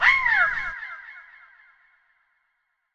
Category 🌿 Nature
ambient animal bird birdsong delay dub echo effect sound effect free sound royalty free Nature